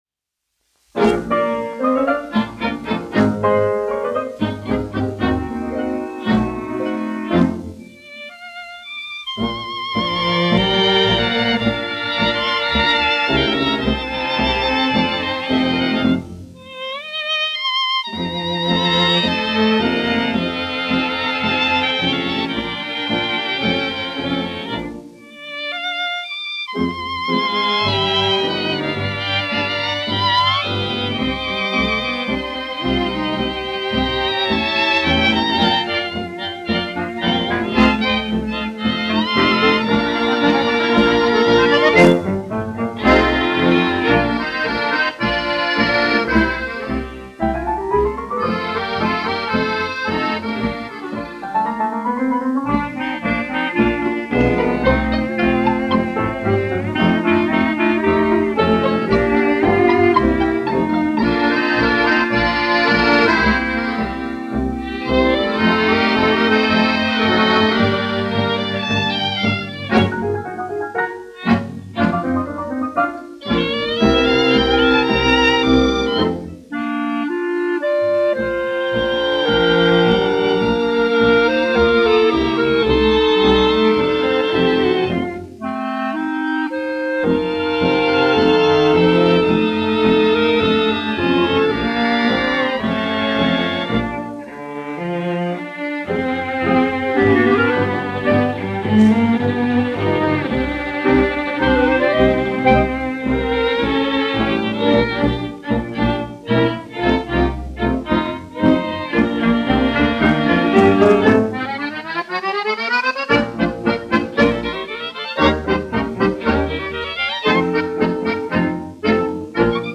1 skpl. : analogs, 78 apgr/min, mono ; 25 cm
Populārā instrumentālā mūzika
Latvijas vēsturiskie šellaka skaņuplašu ieraksti (Kolekcija)